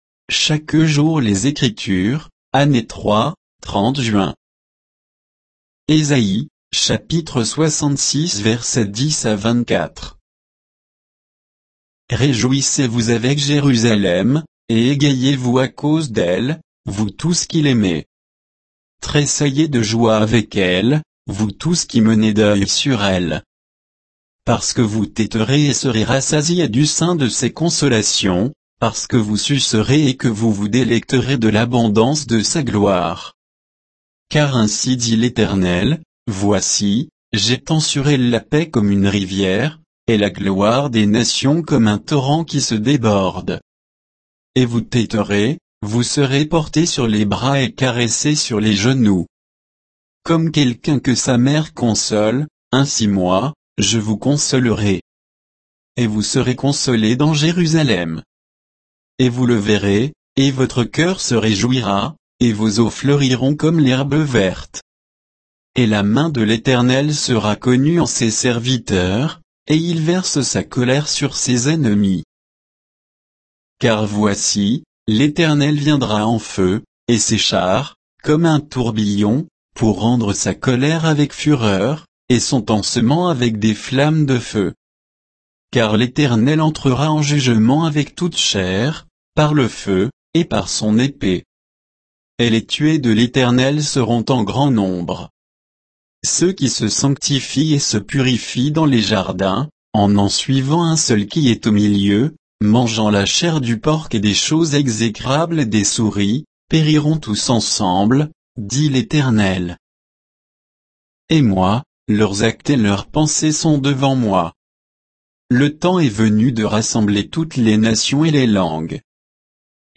Méditation quoditienne de Chaque jour les Écritures sur Ésaïe 66